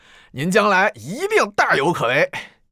c01_9车夫_18.wav